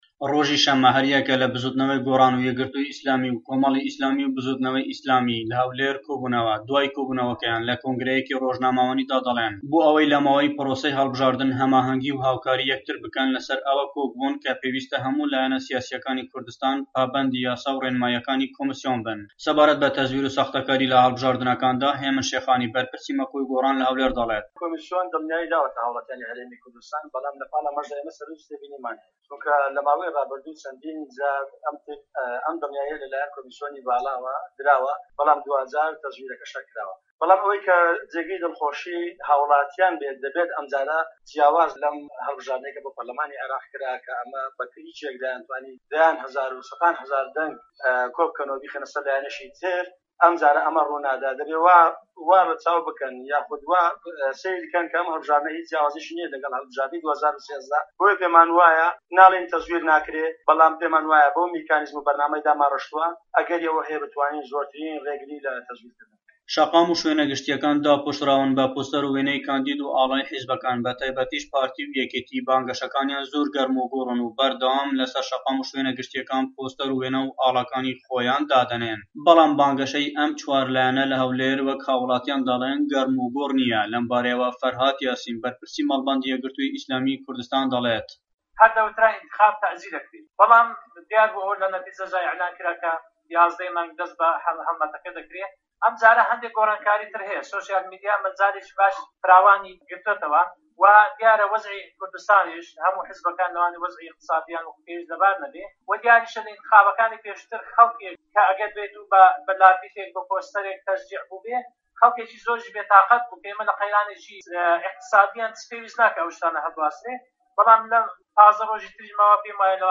دەقی ڕاپۆرتی پەیامنێرمان